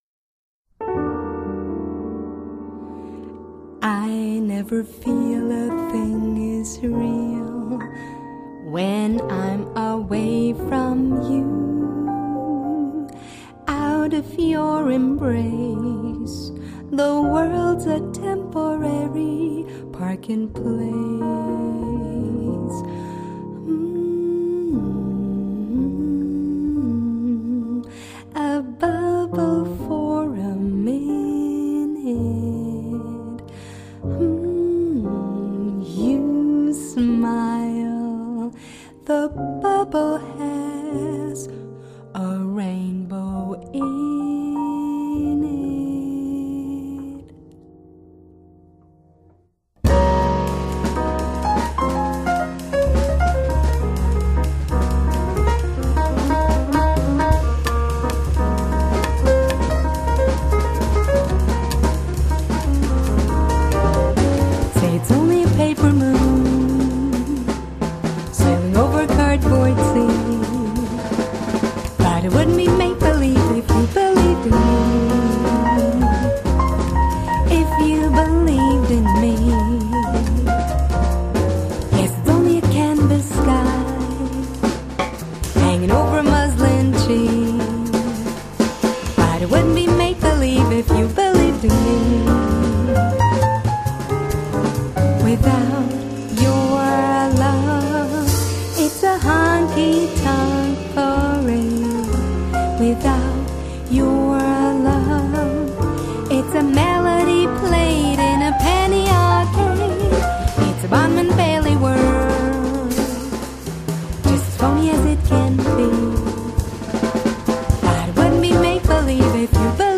the odd-meter tunes on this album